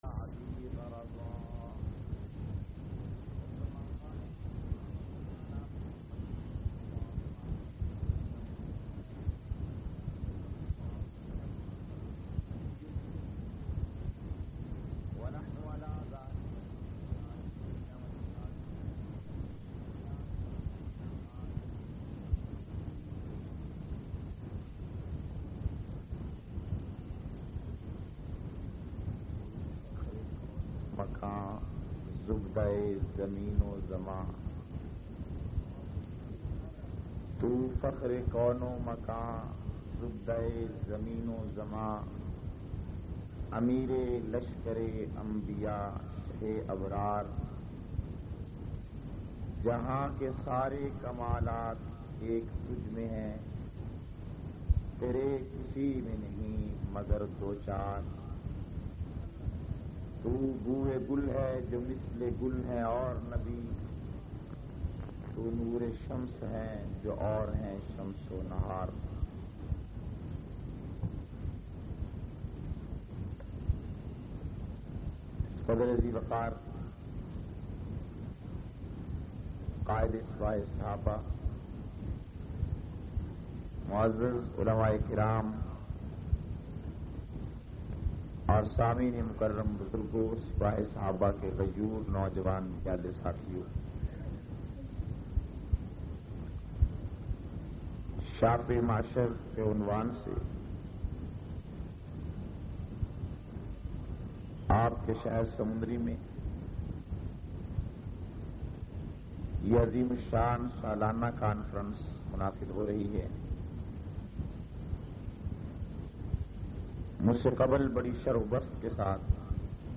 634- Shafay Mehsher Conference-Samundri, Faisalabad.mp3